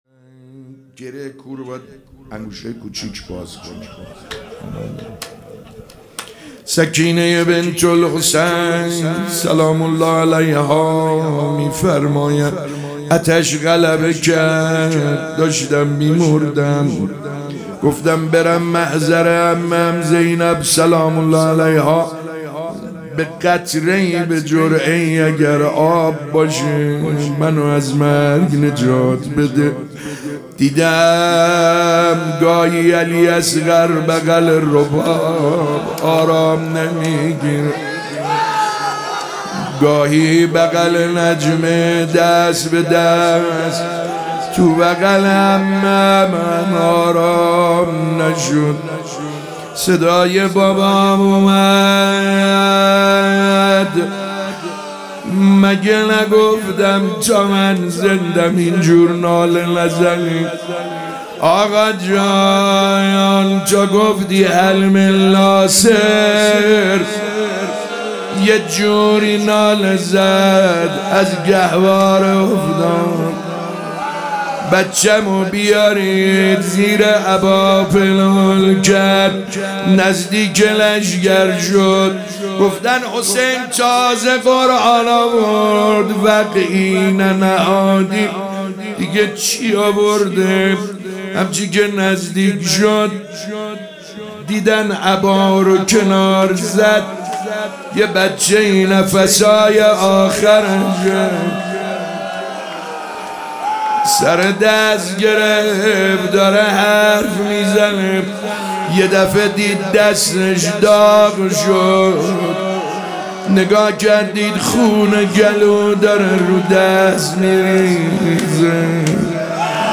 روضه حضرت علی‌اصغر علیه‌السلام